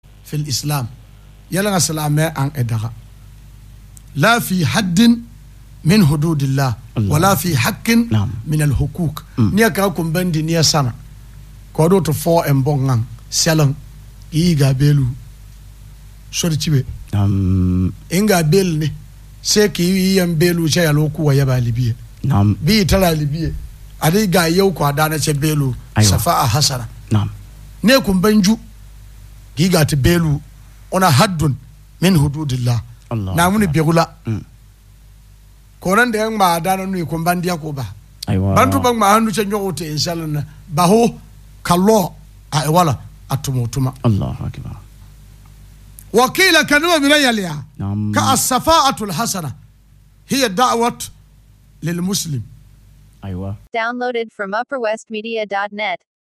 Made the Pronouncements at a Radio Station
Interview